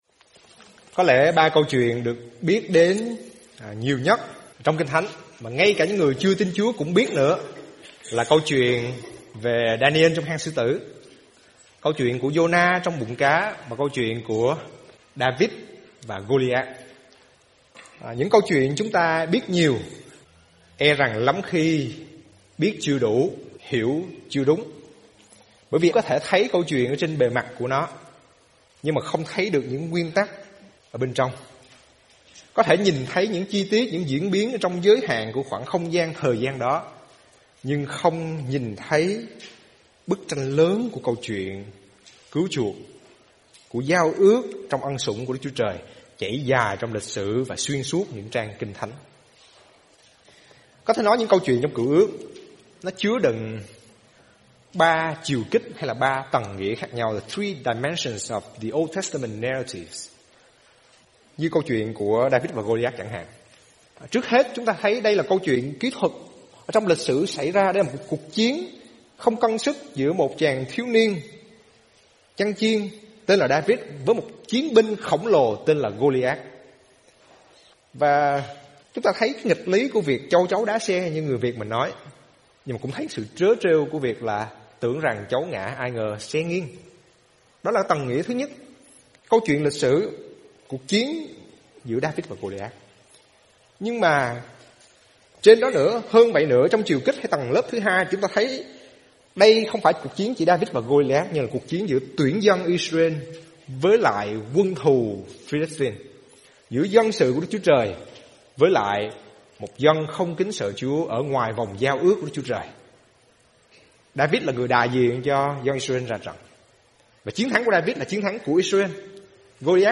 Giảng Luận